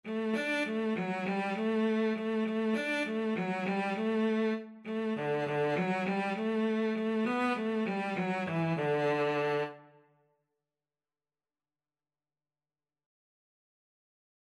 Cello version
Traditional Music of unknown author.
2/4 (View more 2/4 Music)
Quick
D4-D5
World (View more World Cello Music)